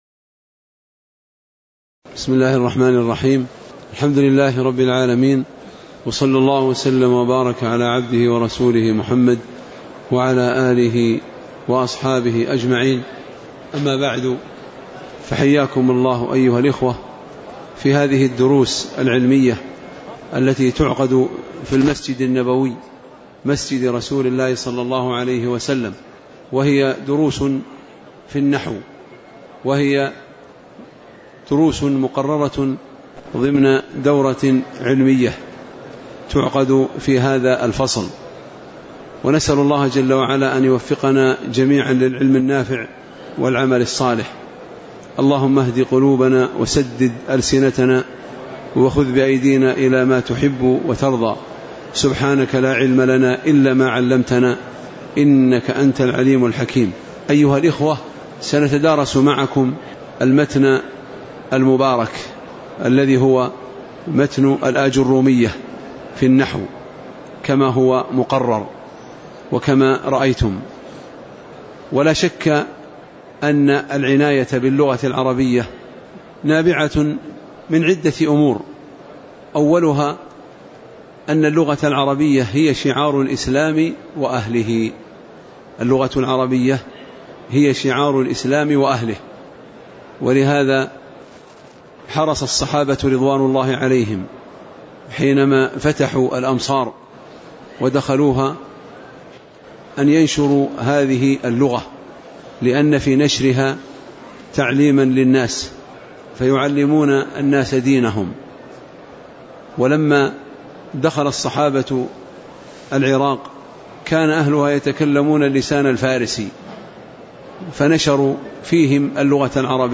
تاريخ النشر ٤ محرم ١٤٤٠ هـ المكان: المسجد النبوي الشيخ